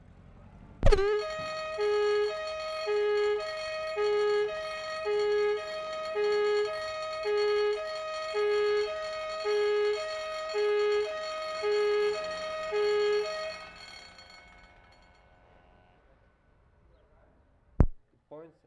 Amsterdam, Holland April 24/75
12. Siren's interval: P5